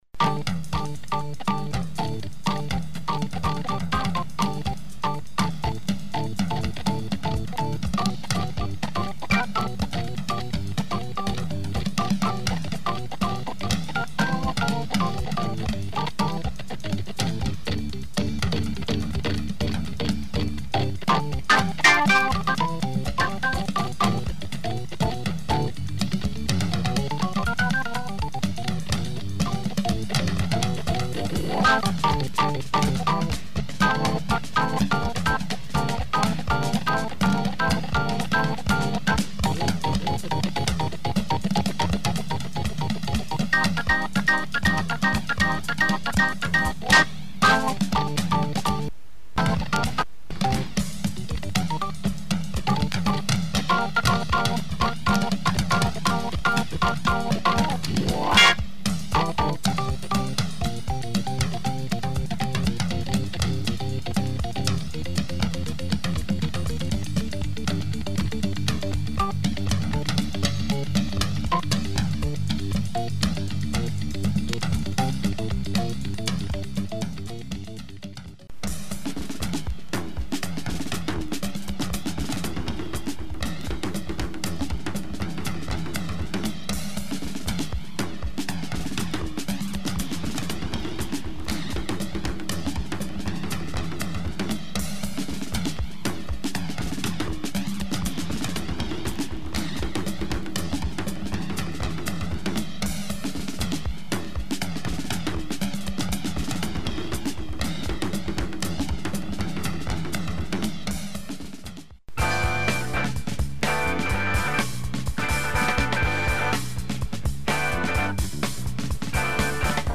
prog organ groove
phasing drums
dope groovy organ pop track
has an aquatic feel, with organ and FX
cool pop groove organ tune